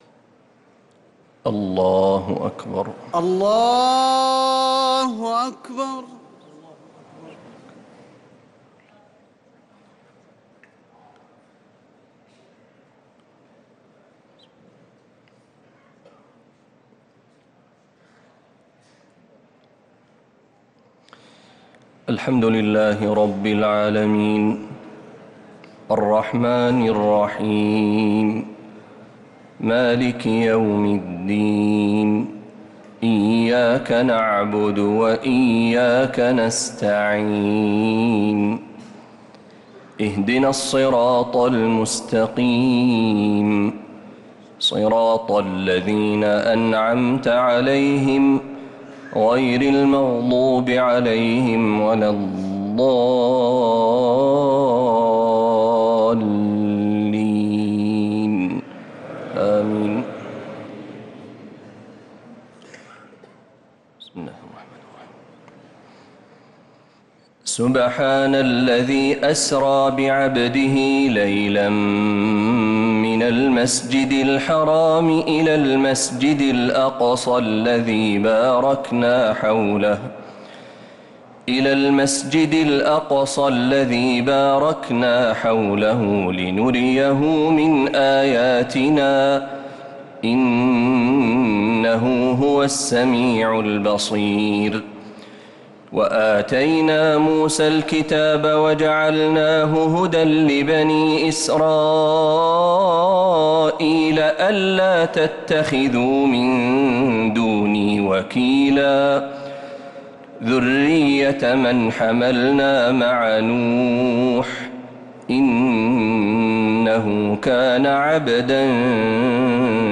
تراويح ليلة 19 رمضان 1446هـ من سورة الإسراء (1-52) | taraweeh 19th night Ramadan 1446H Surah Al-Isra > تراويح الحرم النبوي عام 1446 🕌 > التراويح - تلاوات الحرمين